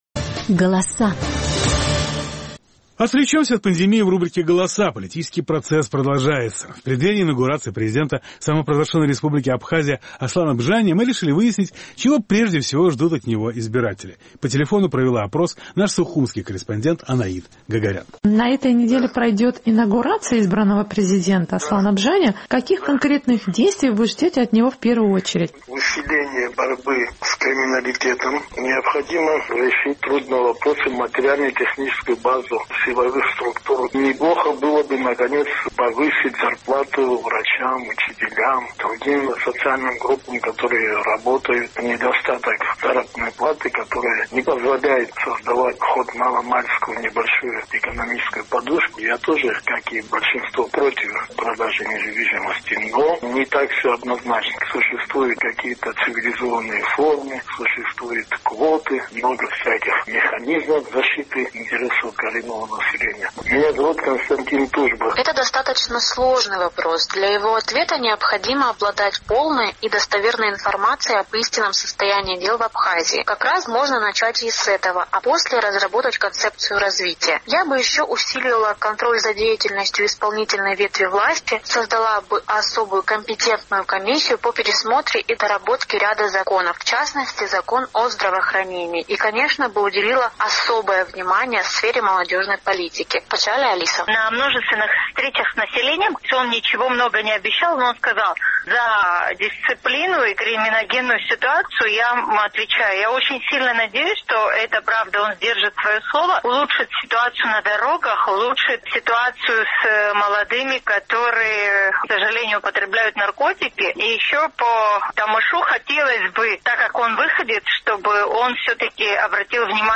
На этой неделе пройдет инаугурация избранного президента Аслана Бжания. Наш сухумский корреспондент поинтересовалась у горожан, каких конкретных действий они ждут от него в первую очередь.